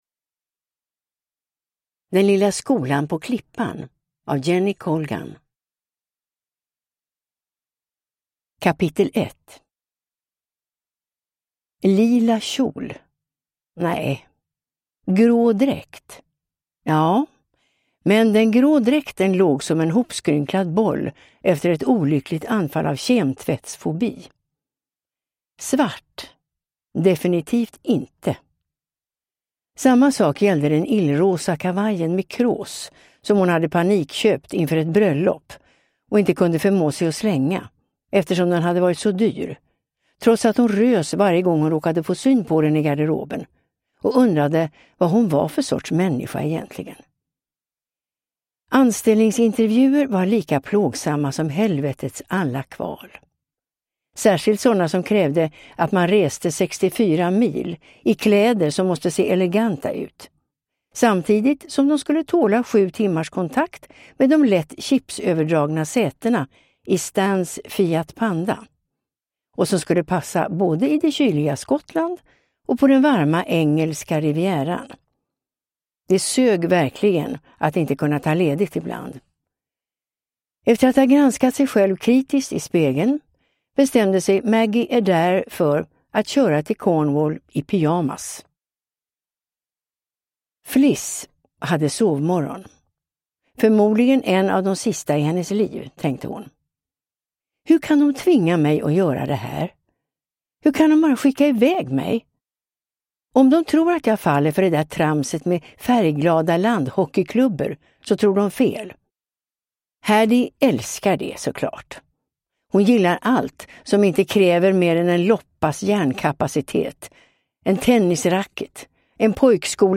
Den lilla skolan på klippan (ljudbok) av Jenny Colgan